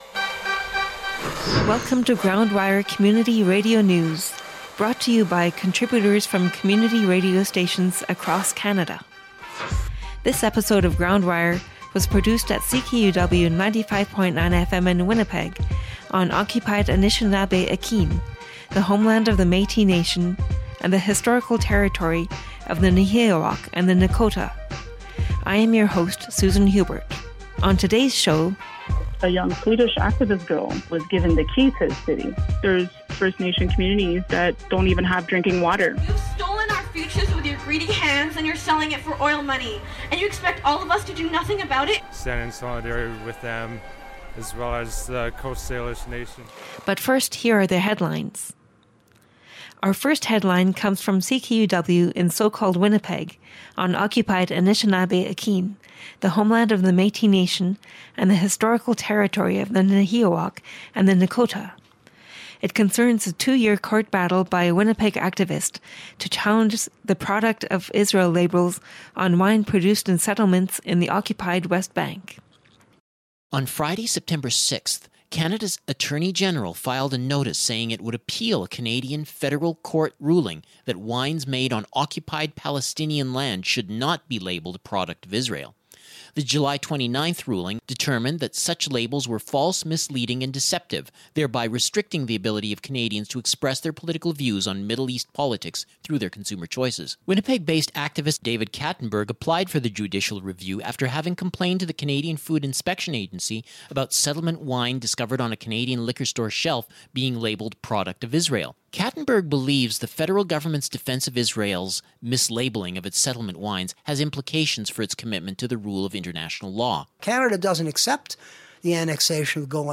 Community radio news from coast to coast to coast